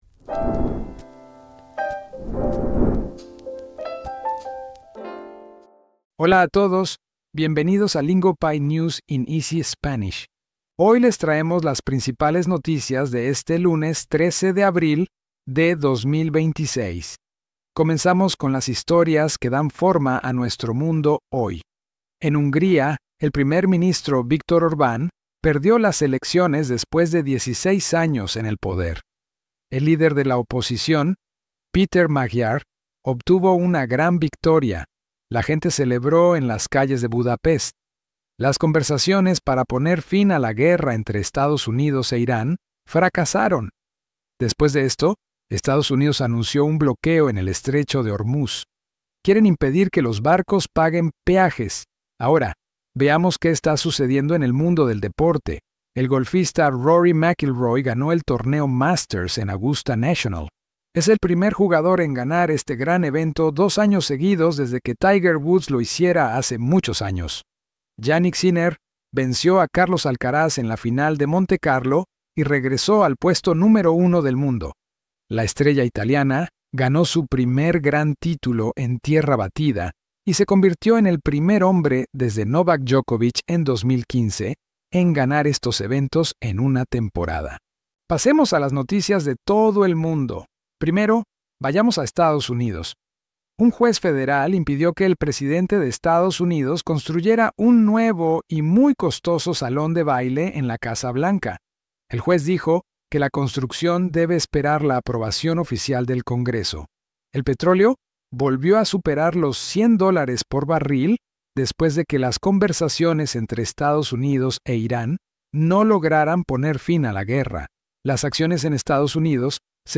This episode breaks down the day’s headlines in clear, beginner-friendly Spanish so you can follow the story without getting stuck.